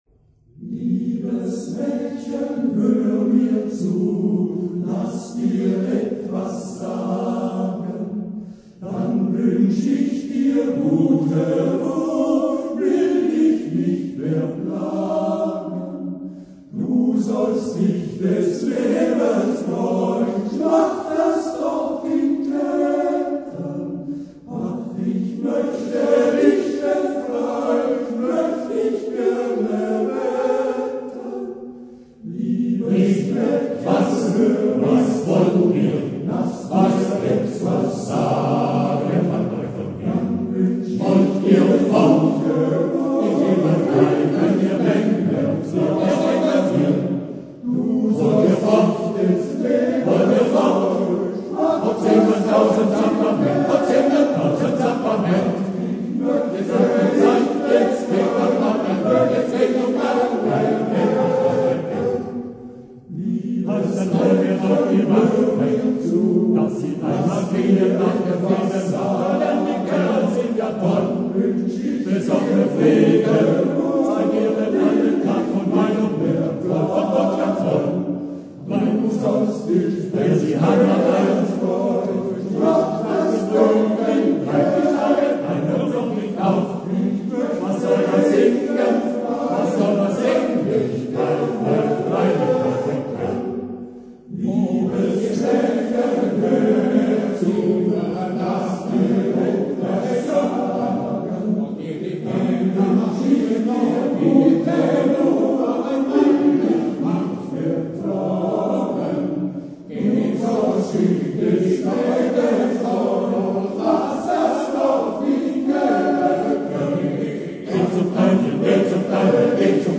Der Männerchor „Liederkranz am Ottenberg“ erreichte mit seinem Gesang am Sängerfest in Bussnang am 4. September 2010 die Bestnote „vorzüglich“, welche nur siebenmal vergeben wurde.
Beim Ständchen singen die Tenöre die bekannte Melodie, wobei die Bässe plötzlich anfangen zu meckern, dass der Verehrer vor dem Fenster endlich Ruhe geben soll.
Dann ging es zur evang. Kirche Bussnang.